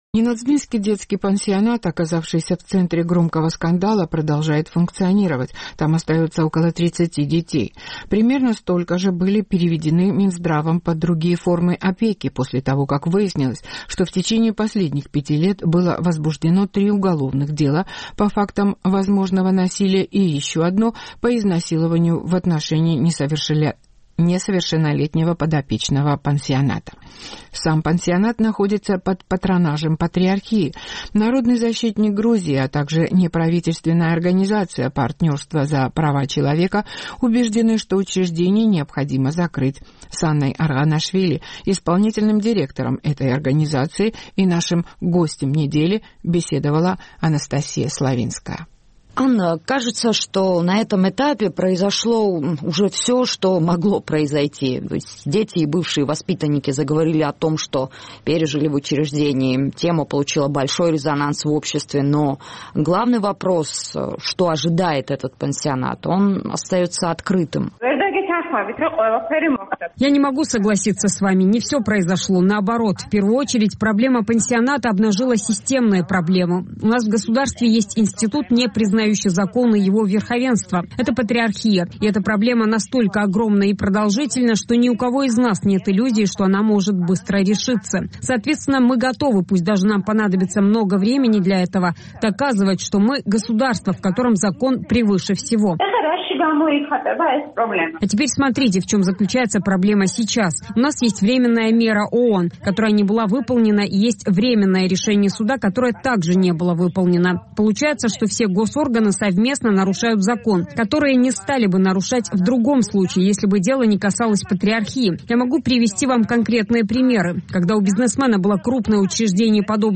Гость недели